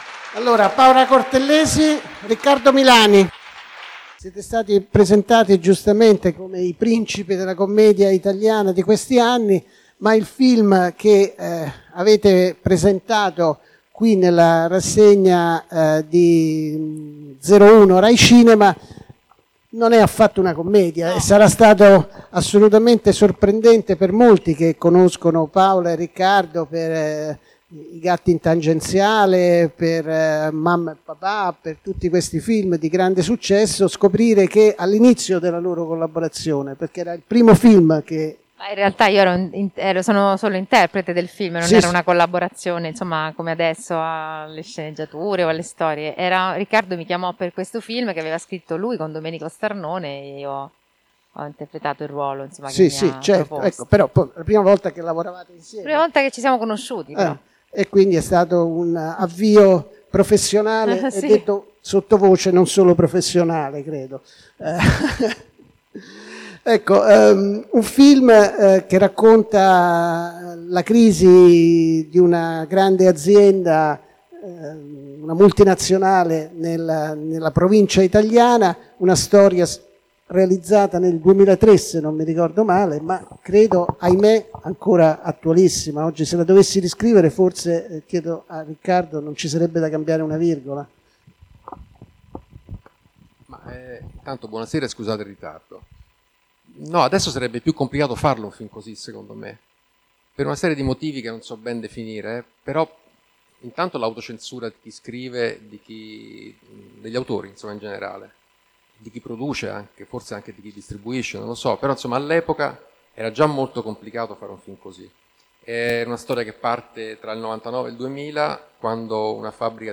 Per la prima giornata del Bifest 2021, il 25 settembre sono continuati i focus 01 Distribution al teatro Margherita